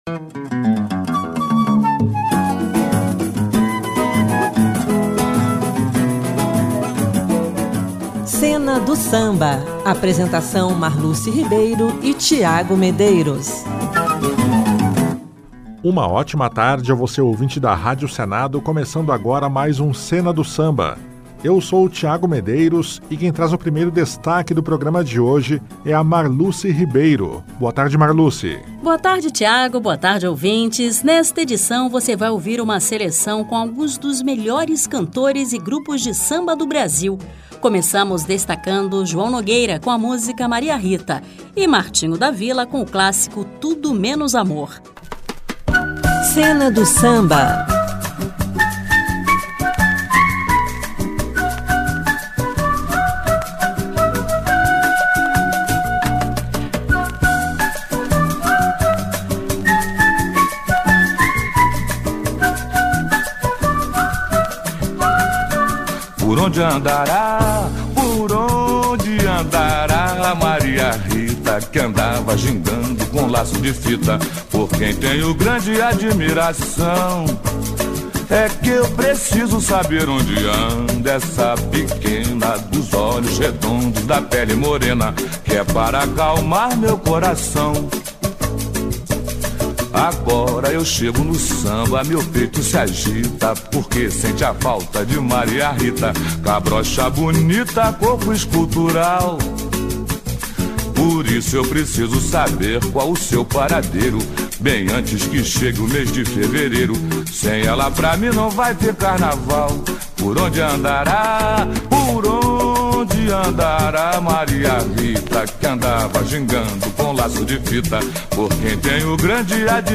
sambas-enredo
sambas clássicos